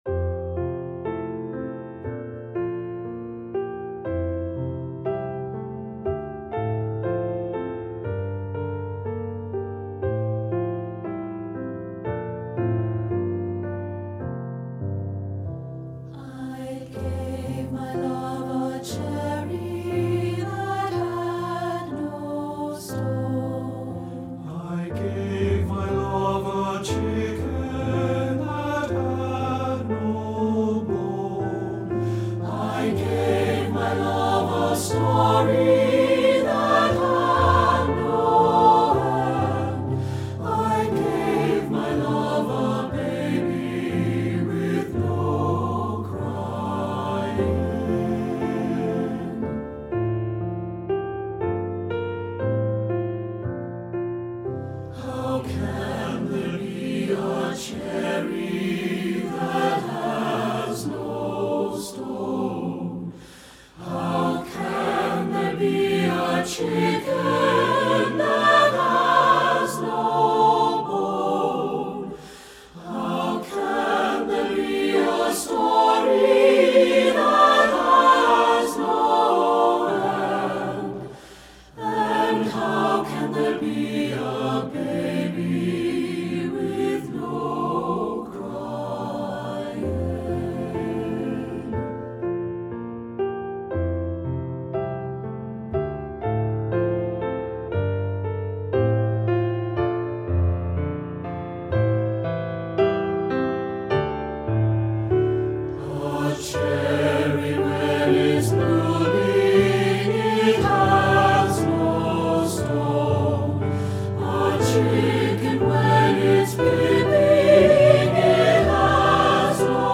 Composer: Appalachian Folk Song
Voicing: SATB and Piano